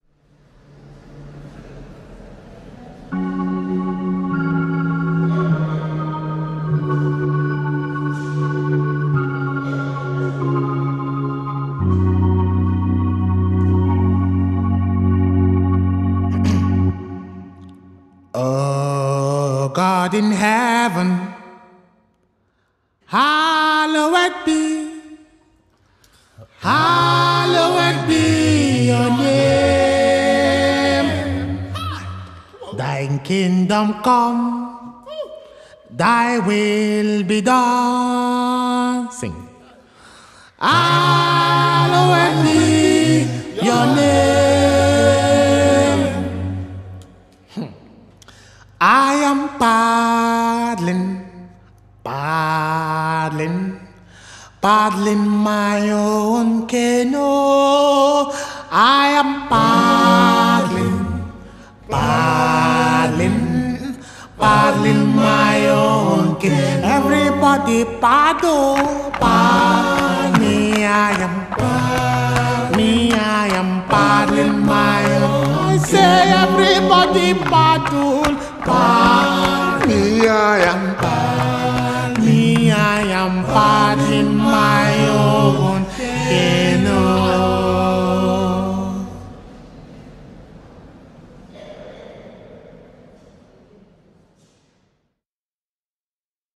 Talented Nigerian duo singer and songwriter